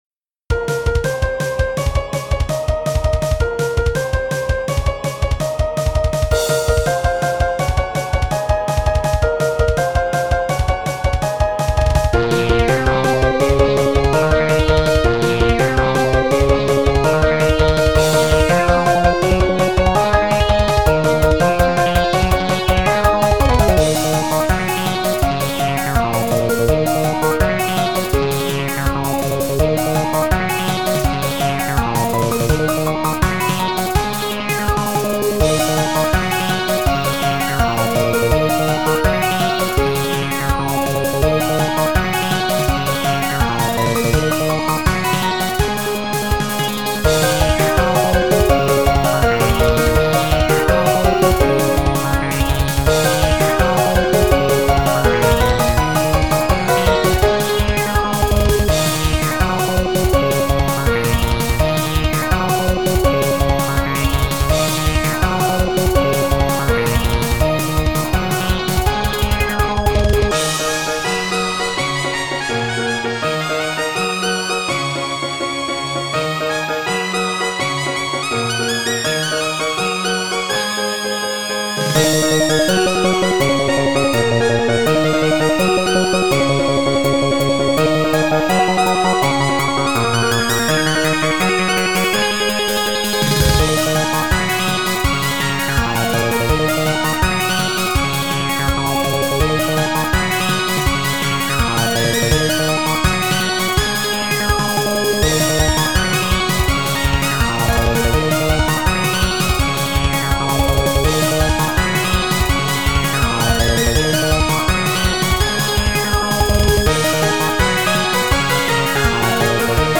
ジャンル Pop(いやまた Pop か！)
割と綺麗に纏まってると思います。